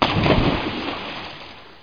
1 channel
in_watr.mp3